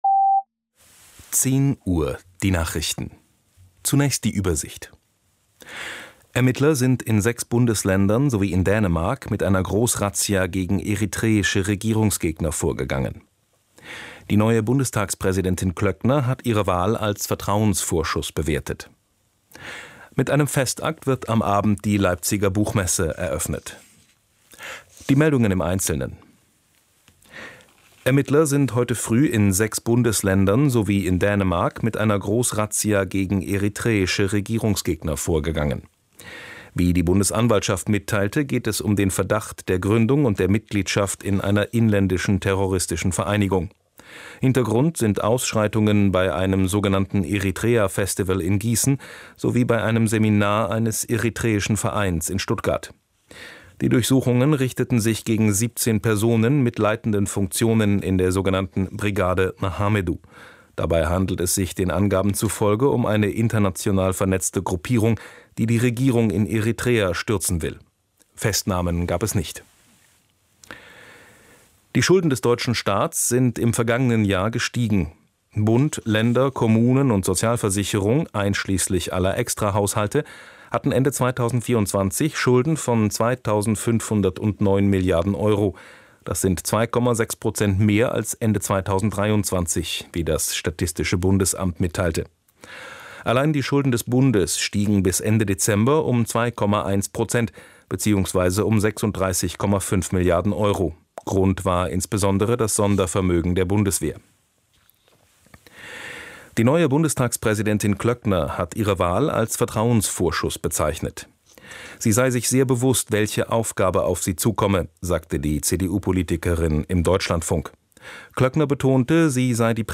sehr variabel, dunkel, sonor, souverän
Mittel minus (25-45)
Audio Drama (Hörspiel)